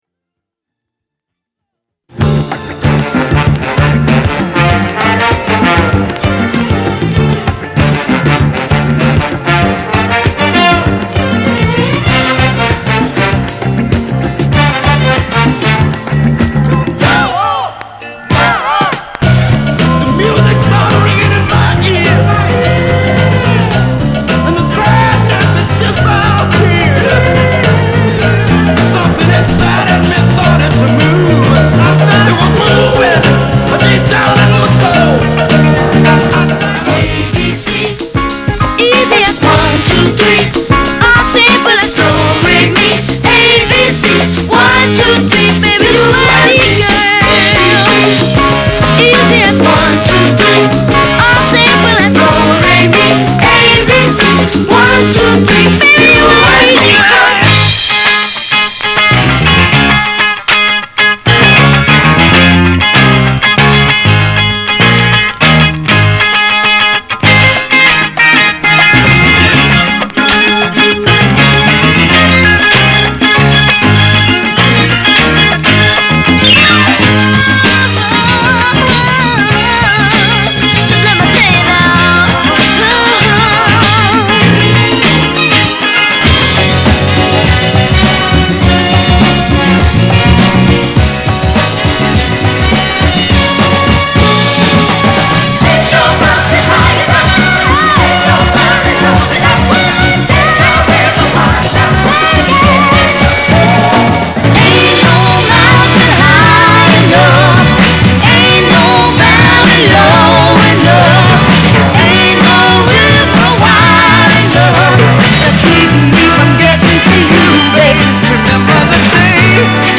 /盤質/両面全体に傷あり/US PRESS